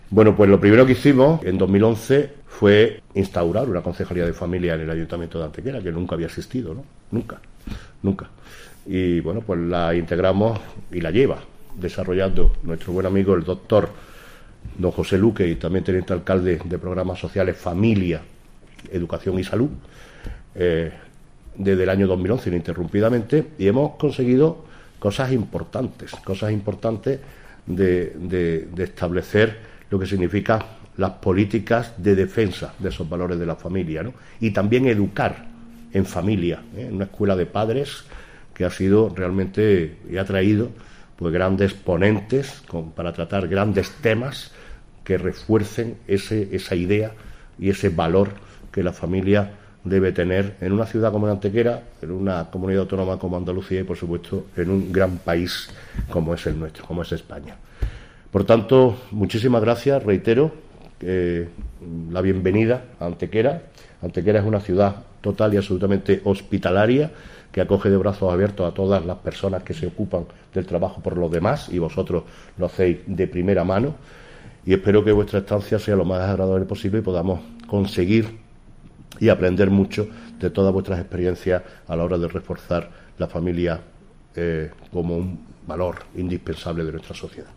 En dicho acto, desarrollado en el salón de actos del Museo de la Ciudad (MVCA), han estado presentes también el presidente nacional de la Red de Municipios por la Familia, Ángel Juárez, y el delegado en Andalucía de este colectivo, Rafael Belmonte, concejales en los ayuntamientos de Leganés y Sevilla respectivamente.
Cortes de voz M. Barón 599.11 kb Formato: mp3